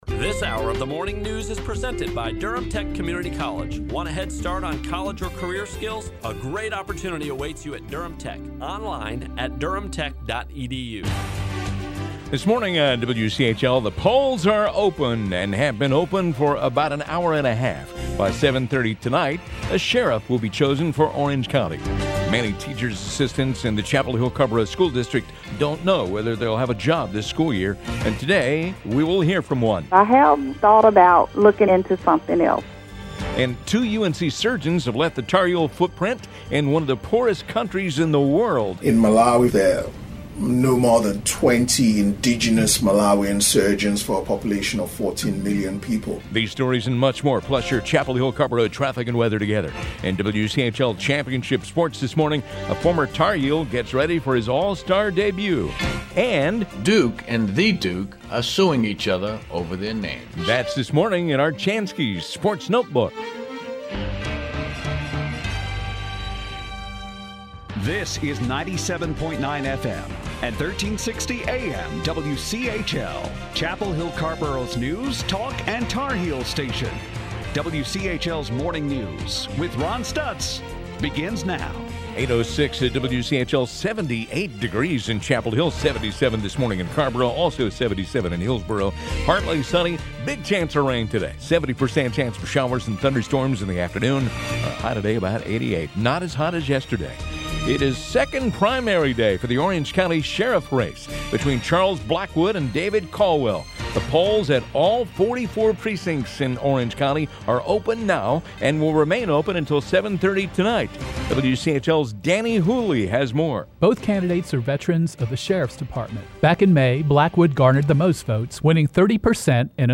WCHL MORNING NEWS_HOUR 3.mp3